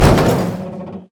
car-metal-impact-2.ogg